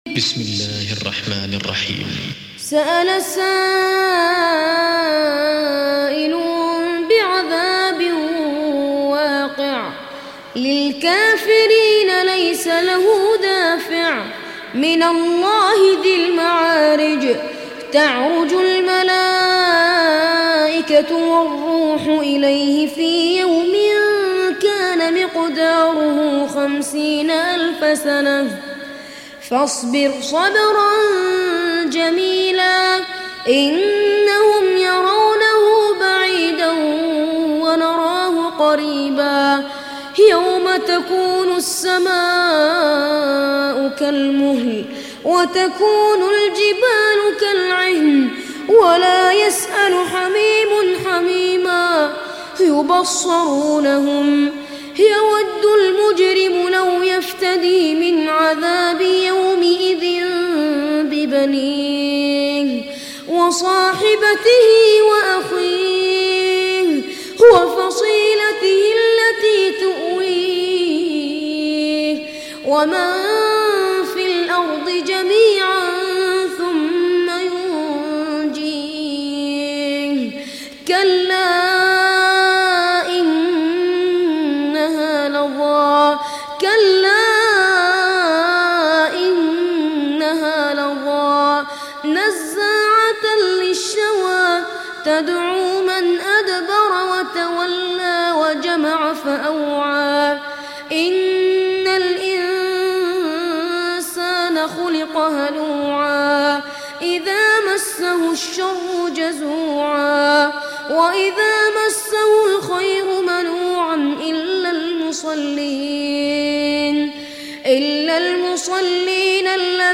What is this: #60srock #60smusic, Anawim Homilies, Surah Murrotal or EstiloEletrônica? Surah Murrotal